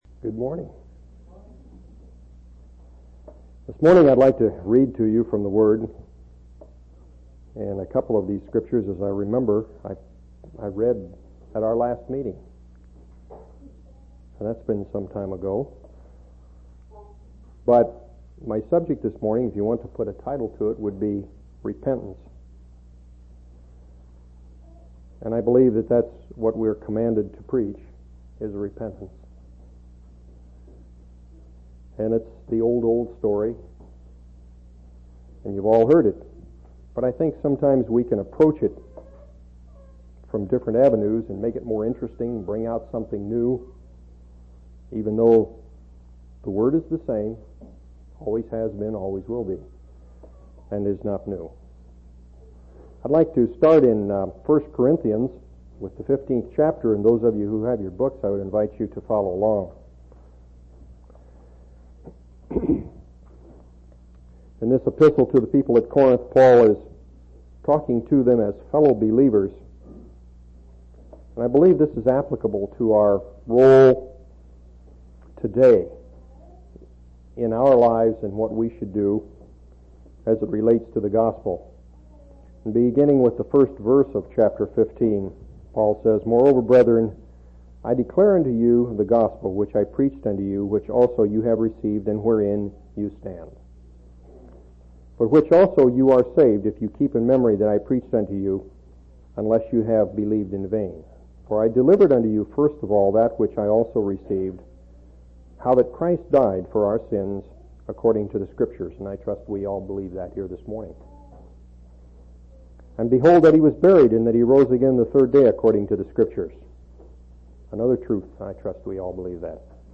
10/22/1989 Location: Temple Lot Local Event